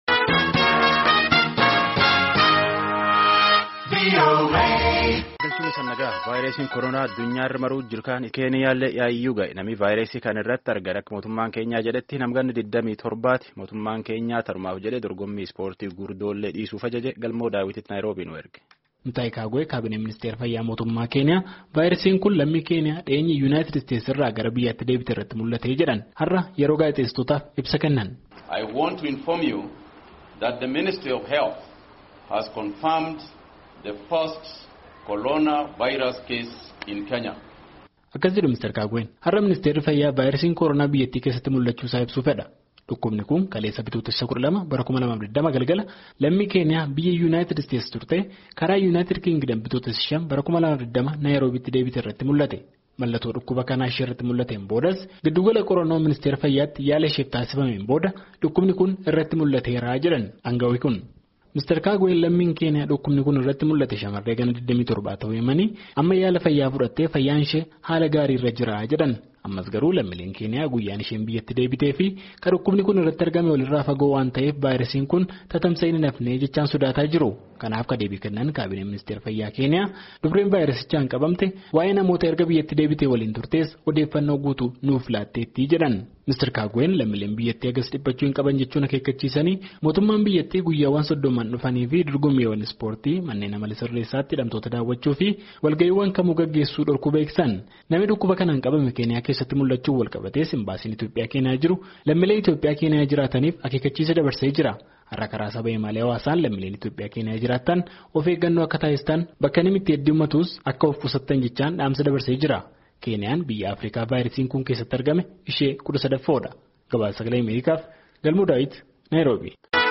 Mutaahii Kaagwee Kaabineen ministeera fayyaa Keeniyaa Vaayresiin kun lammii Keeniyaa dhiyeenya Yuunaaytid Isteetis irraa gara biyyatti deebiterratti mullatee jedhan harra yoo gaazexeessitootaaf ibsa kennan.